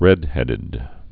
(rĕdhĕdĭd)